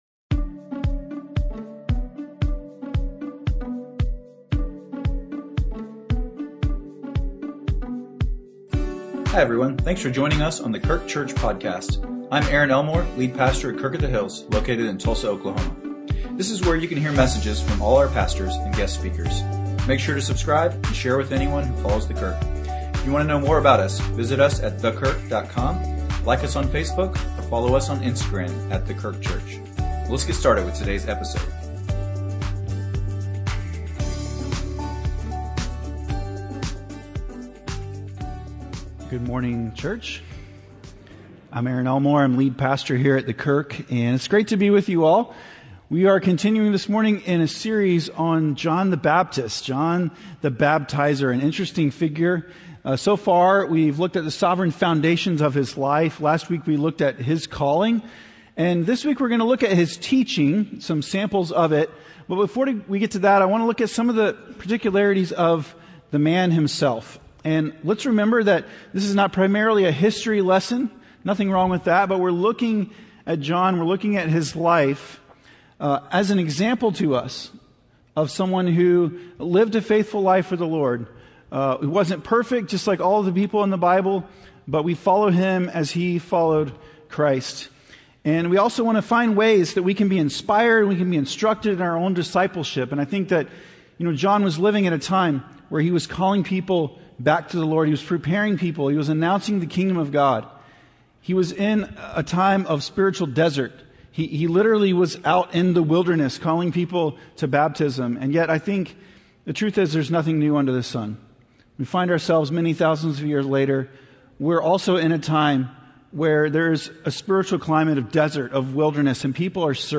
A message from the series "Standing Strong."